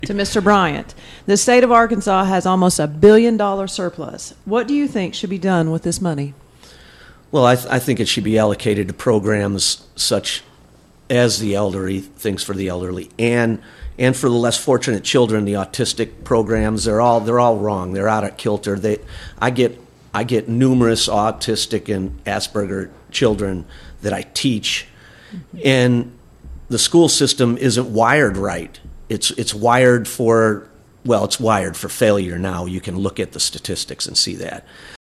Candidates for District 3 State Representative met Friday morning at 9:10 on KTLO-FM.